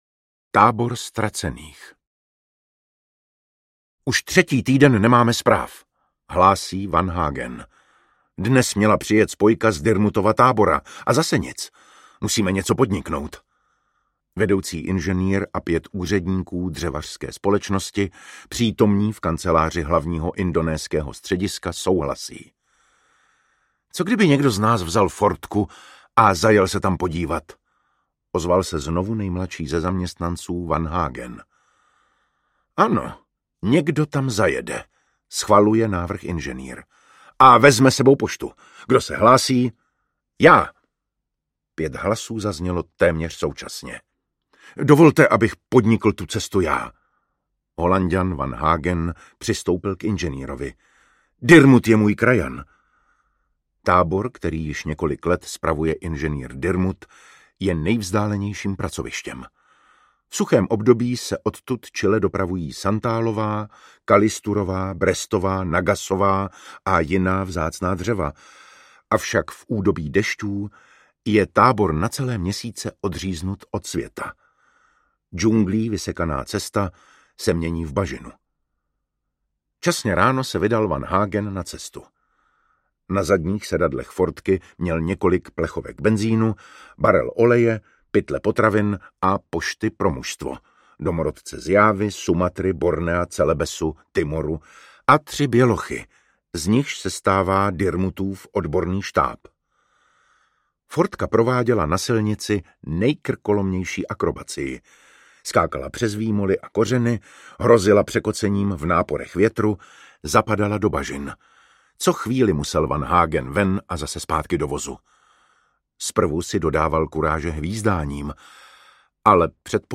V divočině audiokniha
Ukázka z knihy
Čte David Matásek.
Vyrobilo studio Soundguru.